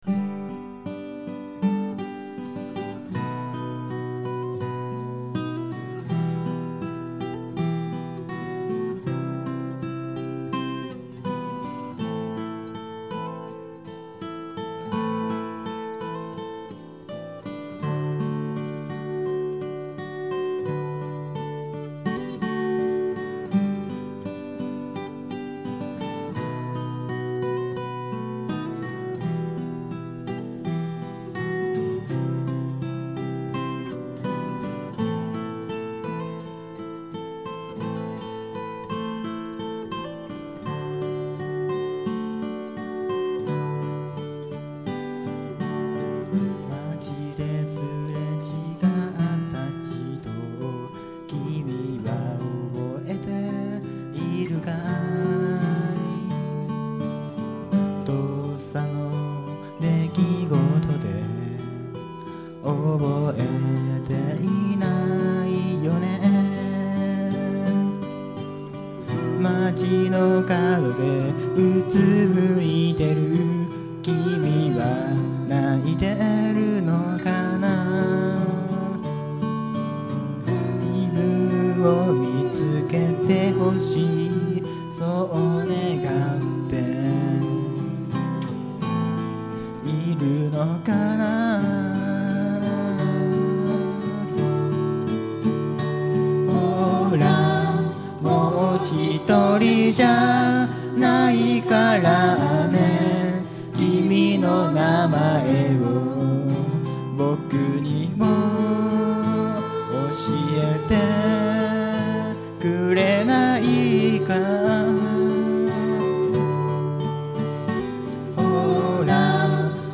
Bluegrass style Folk group
Key of G
企画名: Green Grass活動六周年記念ライブ
録音場所: 御茶ノ水KAKADO
リードボーカル、ギター
コーラス、リズム・ギター
友だちに語りかけるような歌詞とメロディーなので、少し控えめなアレンジにしてみました。